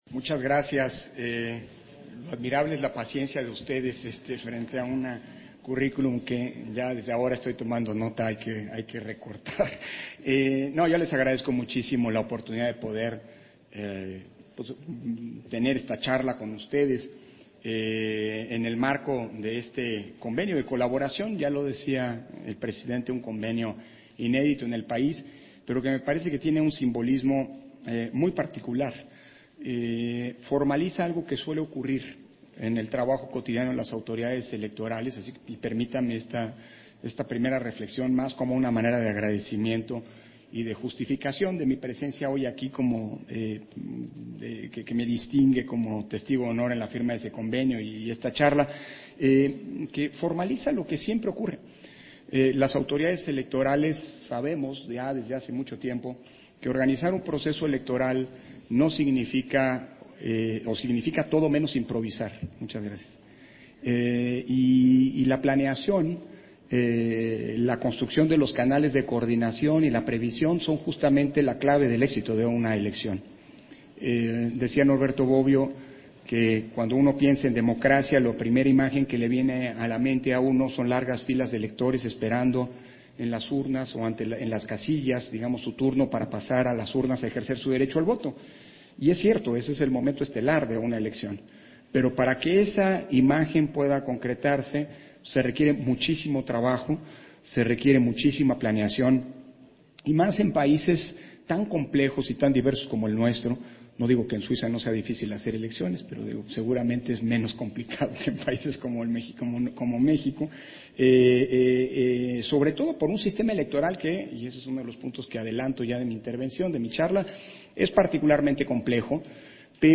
051022_AUDIO_CONFERENCIA-MAGISTRAL-CONSEJERO-PDTE.-CÓRDOVA - Central Electoral